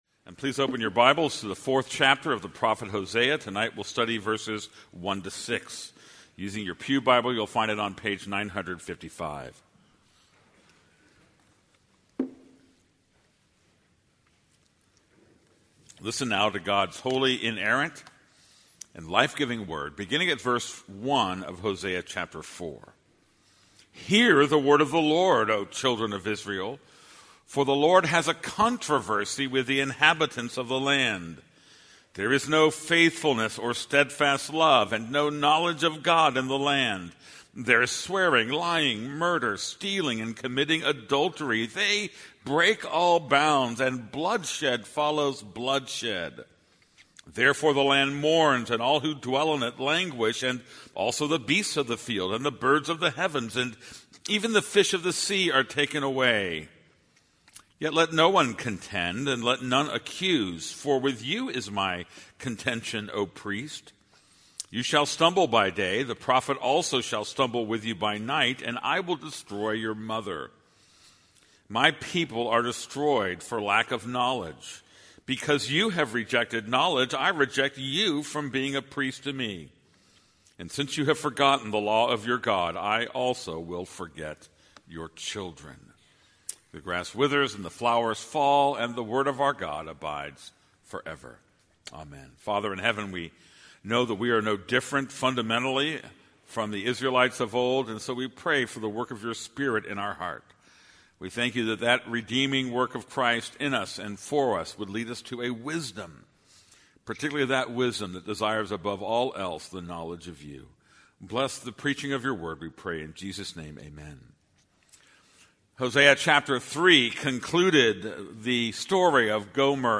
This is a sermon on Hosea 4:1-6.